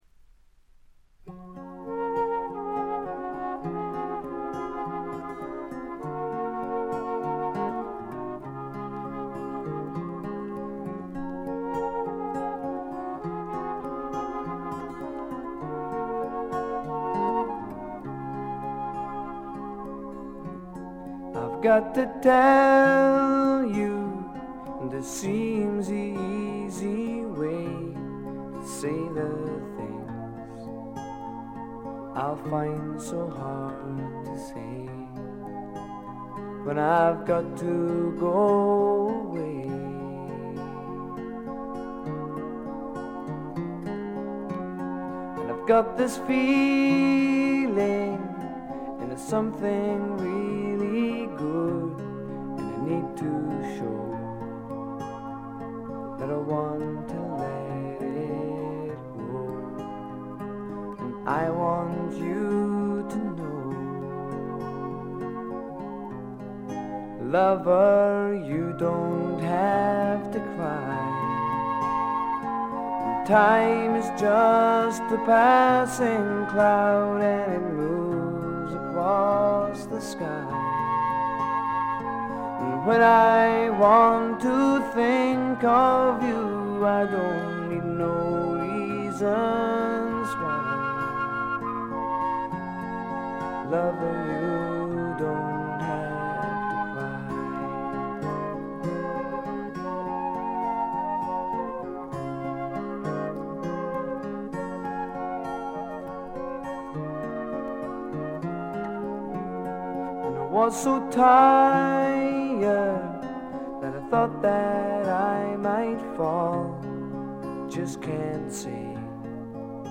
ほとんどノイズ感無し。
試聴曲は現品からの取り込み音源です。
Recorded At Pace Studios, Milton Keynes